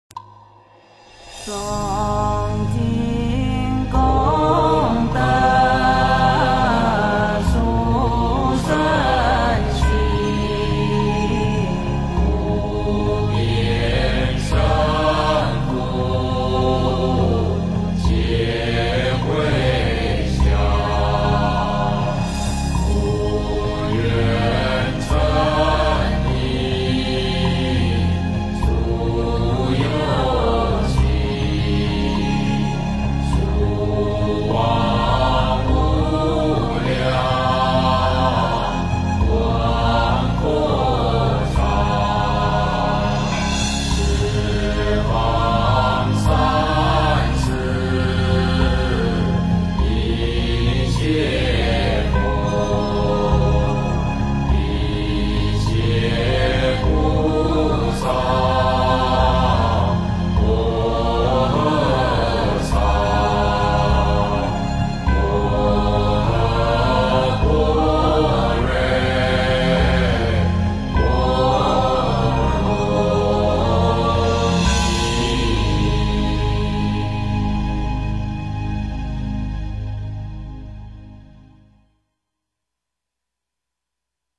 三皈依回向偈 诵经 三皈依回向偈--如是我闻 点我： 标签: 佛音 诵经 佛教音乐 返回列表 上一篇： 啓告十方 下一篇： 药师佛拜愿 相关文章 坐看云端--李志辉 坐看云端--李志辉...